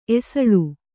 ♪ esaḷu